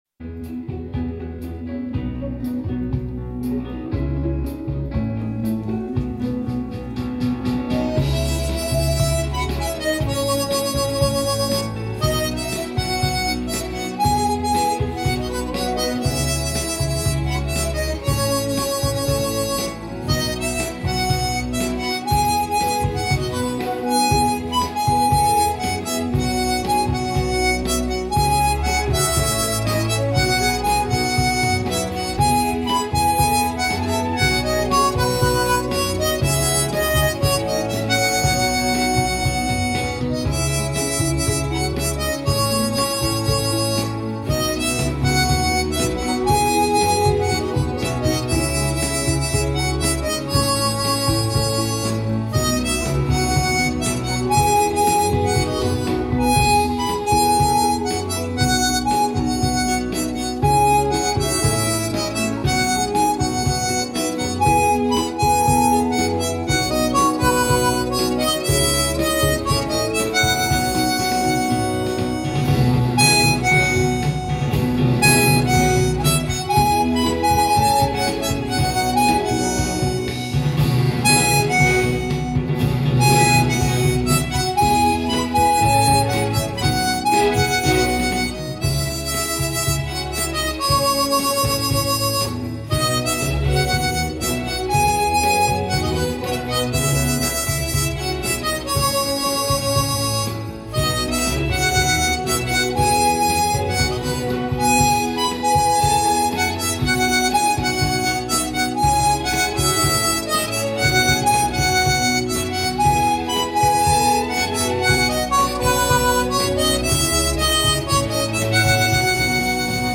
Golden Oldies - Classic Hits  - Sing a Long Songs